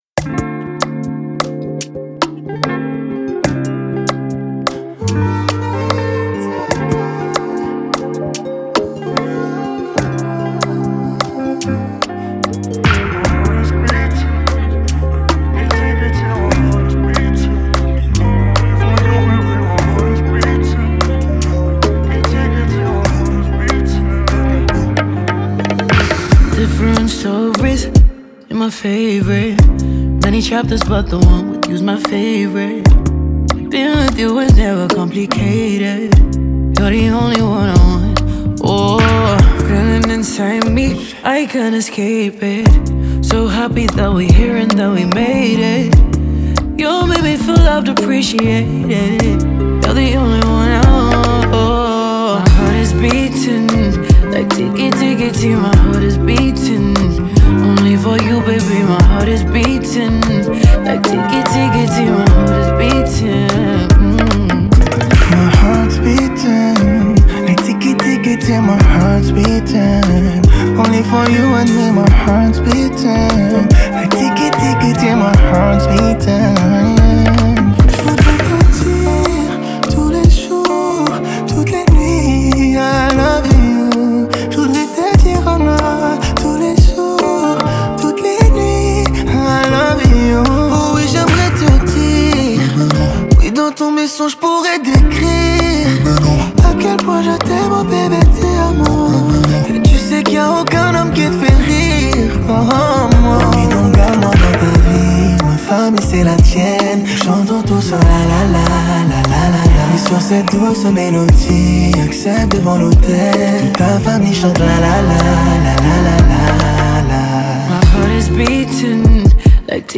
Check the new tune from Holland-Ghanaian singer
in collaboration with French-Congolese artist